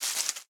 leaves3.ogg